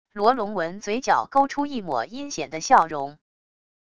罗龙文嘴角勾出一抹阴险的笑容wav音频生成系统WAV Audio Player